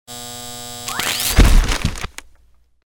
Звук разряда дефибриллятора